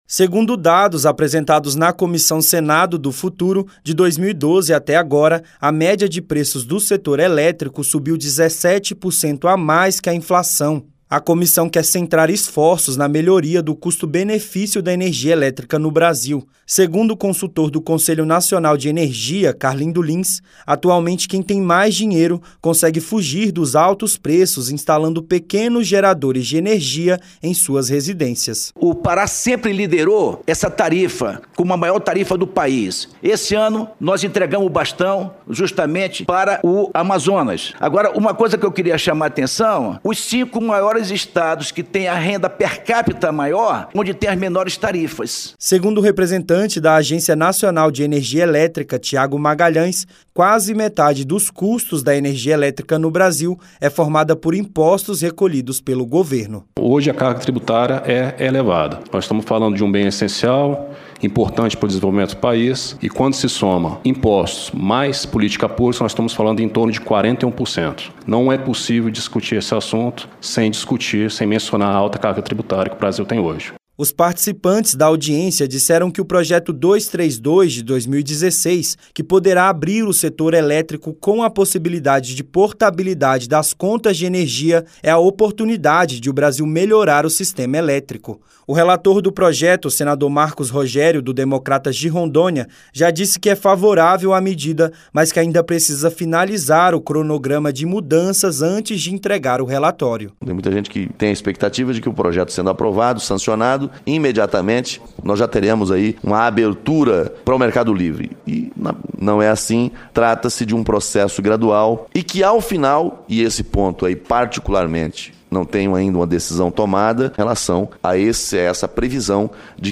Audiência pública